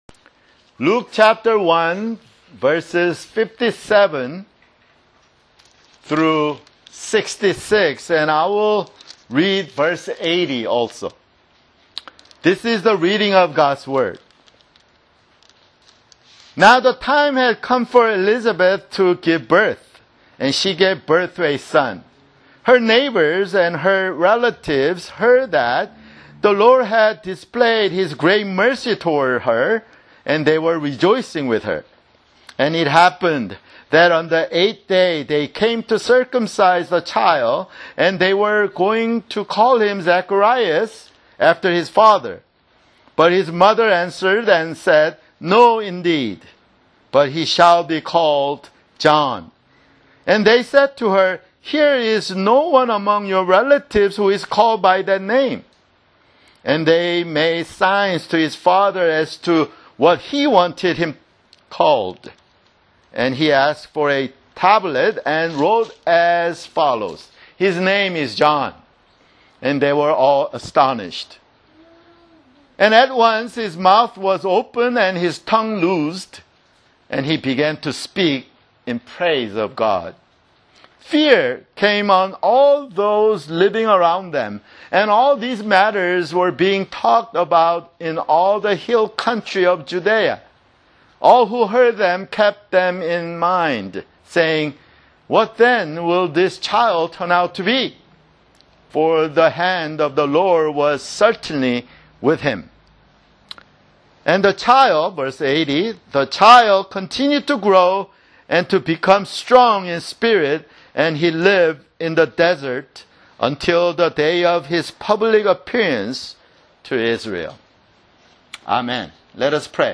[Sermon] Luke (11)